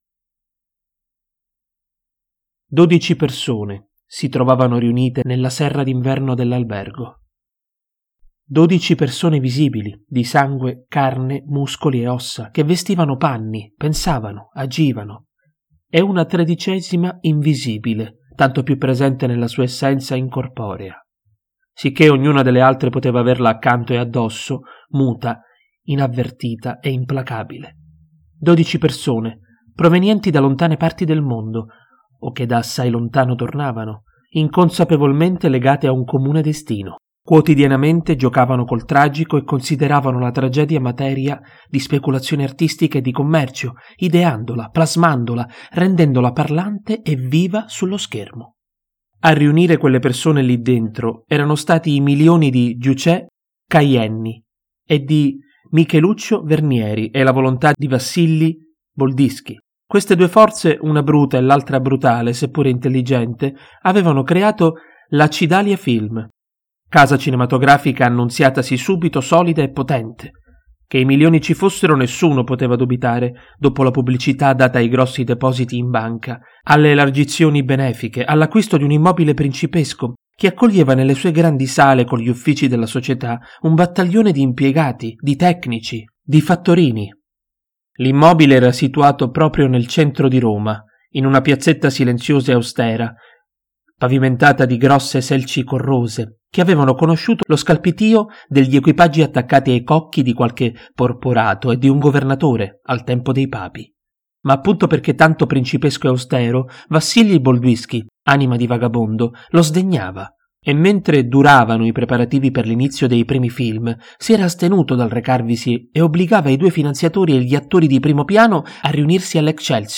De Vincenzi e il mistero di Cinecittà (audiolibro)
Genere: Giallo